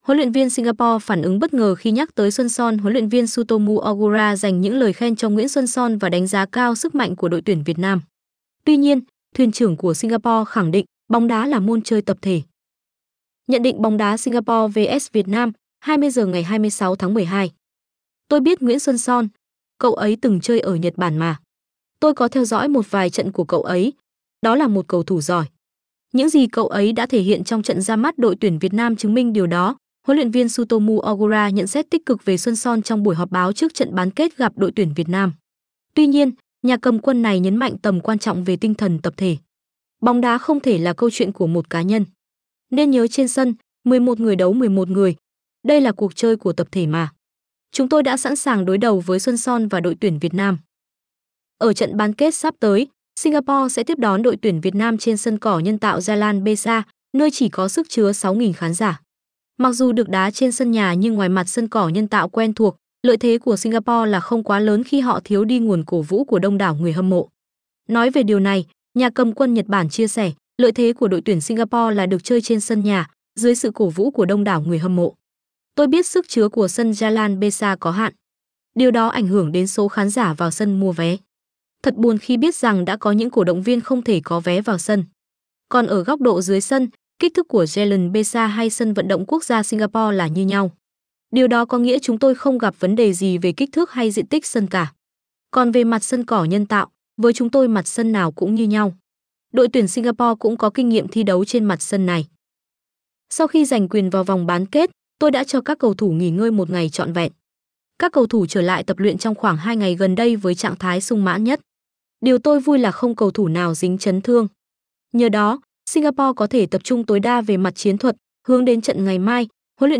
Giọng nữ